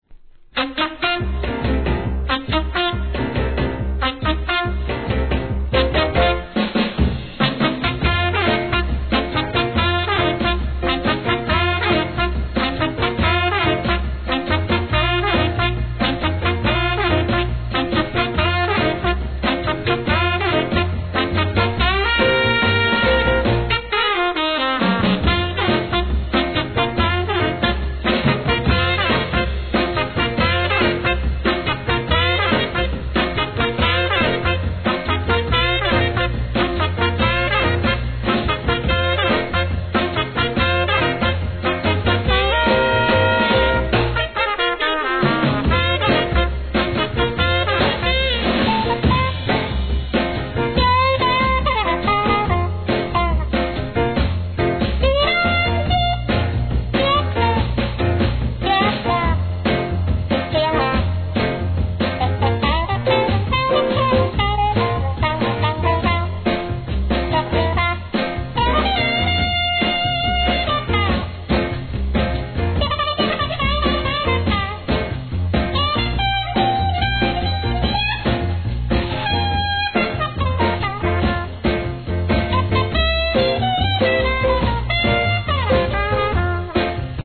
¥ 1,320 税込 関連カテゴリ SOUL/FUNK/etc...